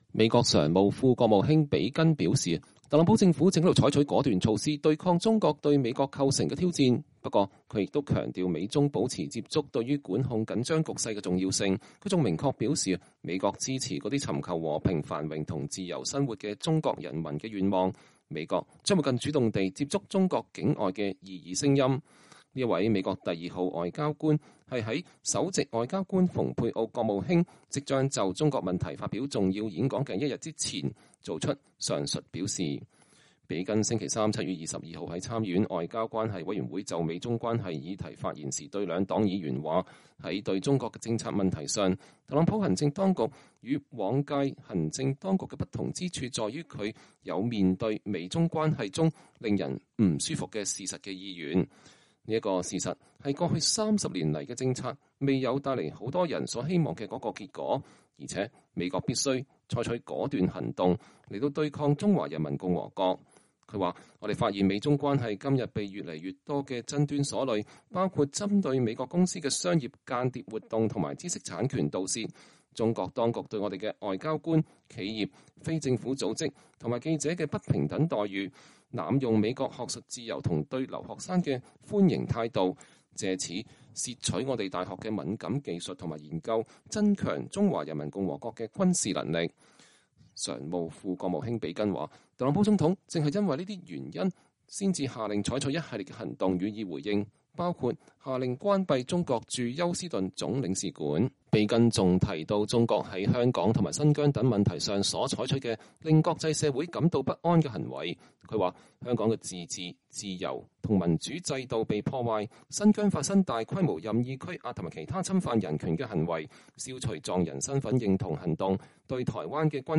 美国常务副国务卿比根在参议院外交关系委员会发言。(2020年7月22日)